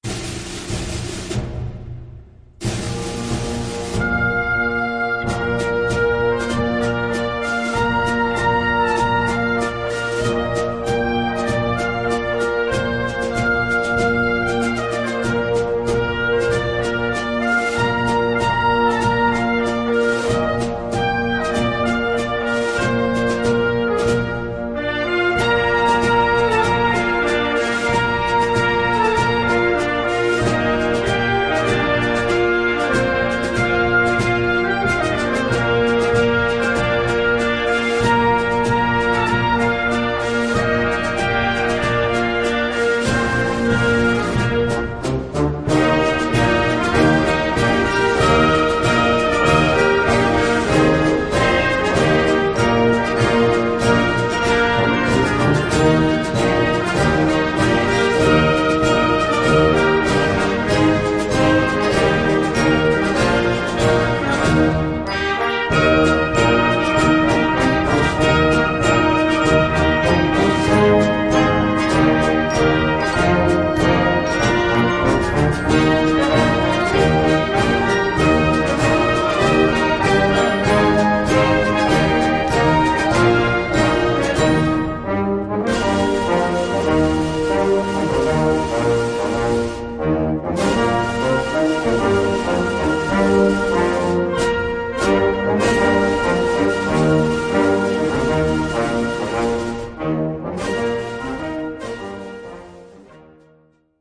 Gattung: Konzertante Blasmusik
Besetzung: Blasorchester
In diesem energiegeladenen Arrangement
treffen zwei typisch schottische Melodien aufeinander.